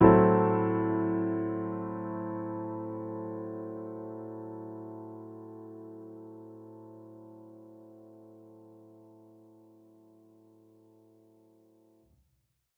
Index of /musicradar/jazz-keys-samples/Chord Hits/Acoustic Piano 1
JK_AcPiano1_Chord-Am6.wav